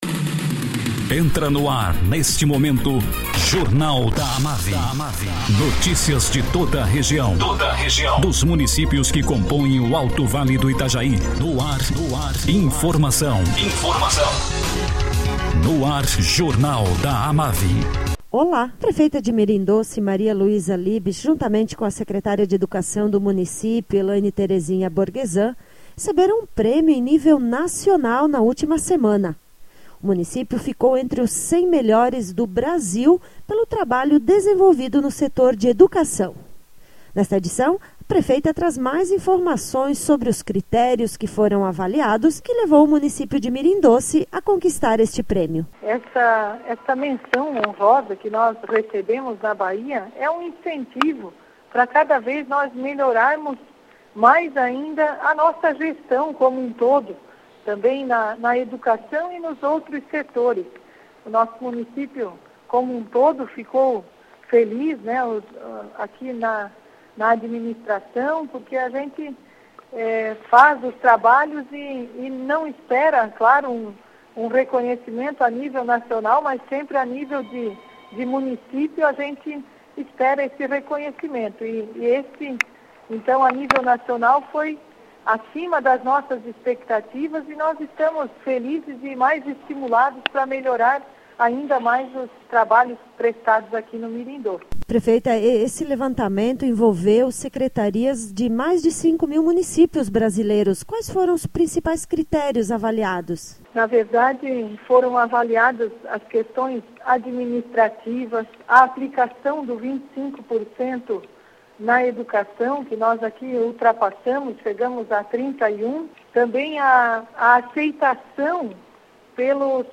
Prefeita de Mirim Doce, Maria Luiza Kestring Liebsch, fala sobre o trabalho desenvolvido nas escolas da rede municipal de ensino, que rendeu ao município a conquista do troféu “Gestor Nota 10?.